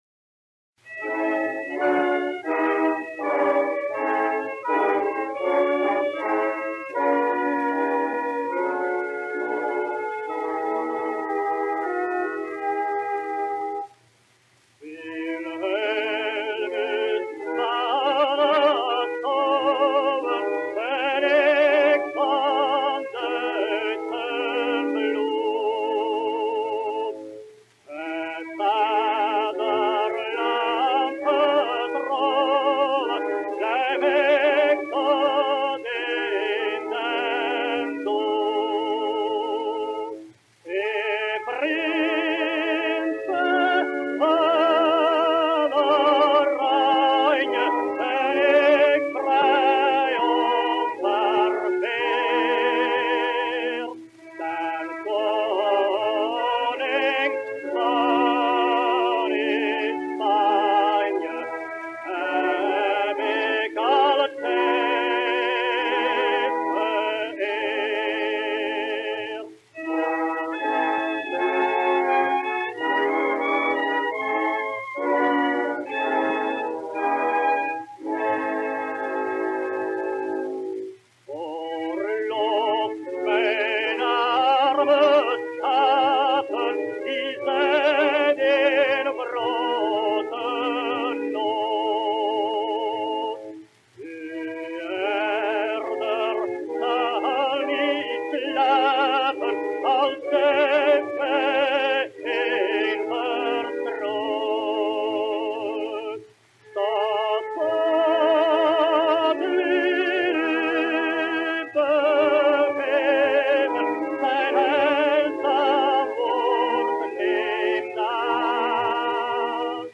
1899년 "빌헬루스"의 첫 번째 녹음